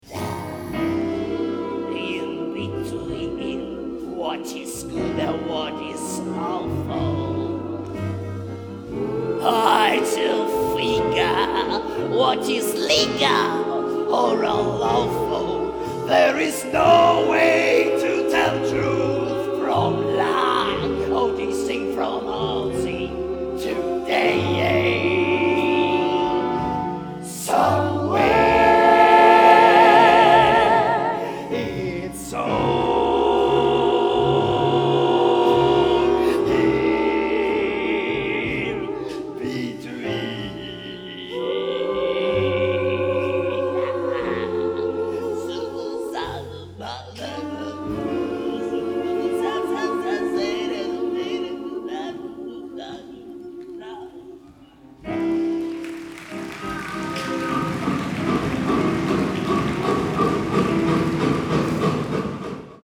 sax contralto
sax tenore
clarinetto
tromba
trombone
chitarra elettrica
pianoforte
basso elettrico
batteria
GenereJazz